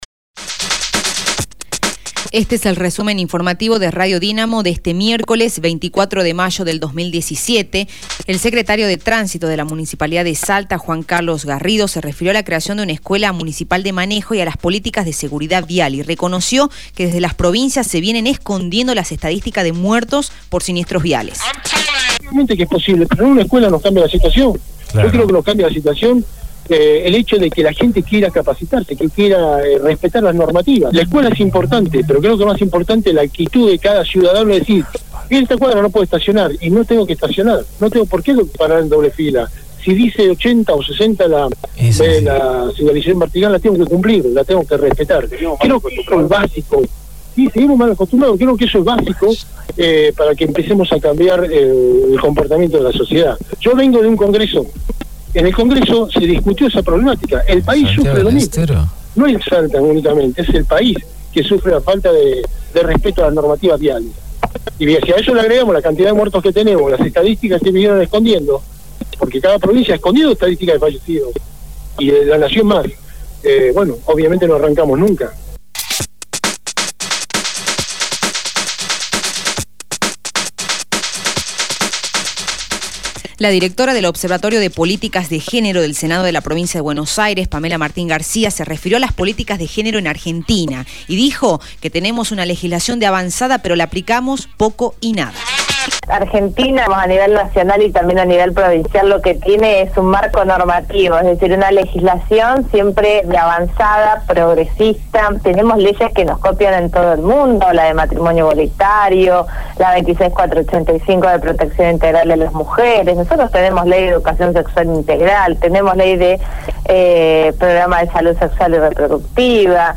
Resumen Informativo de Radio Dinamo del día 24/05/2017 2° Edición